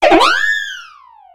Cri de Farfaduvet dans Pokémon X et Y.